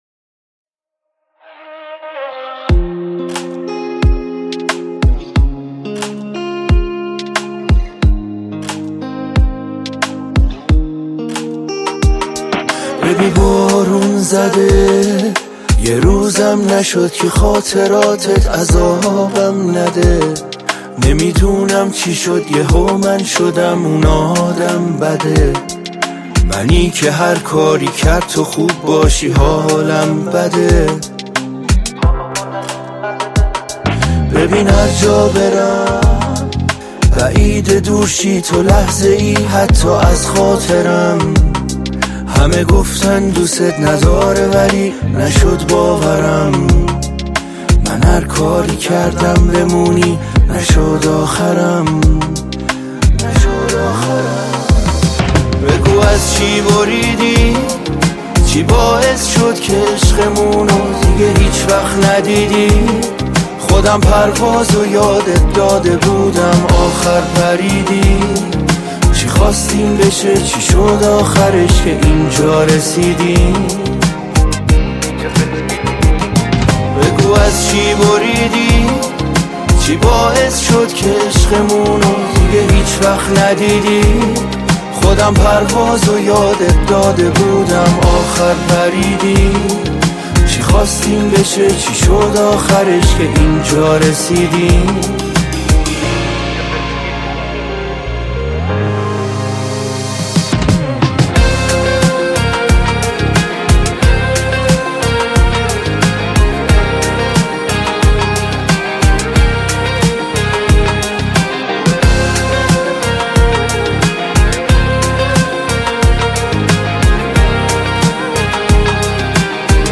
عاشقانه و احساسی